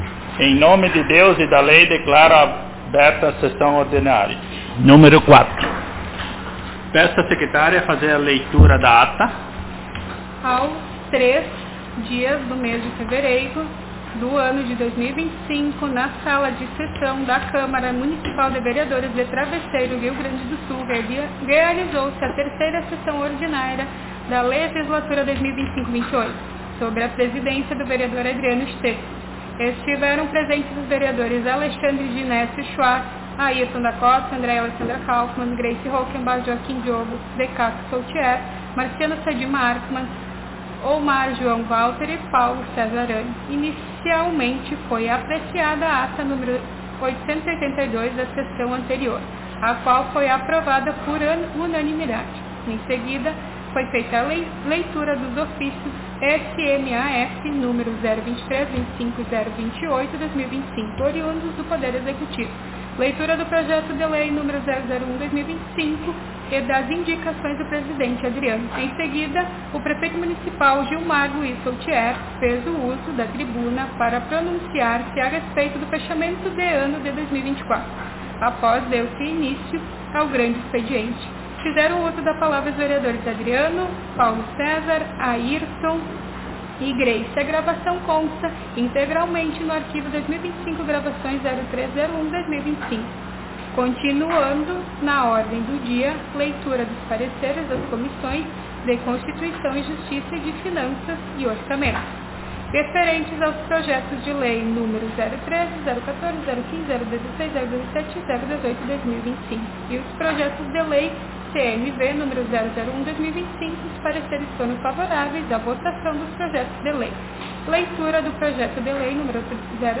Aos 17 (dezessete) dias do mês de fevereiro do ano de 2025 (dois mil e vinte e cinco), na Sala de Sessões da Câmara Municipal de Vereadores de Travesseiro/RS, realizou-se a Quarta Sessão Ordinária da Legislatura 2025-2028.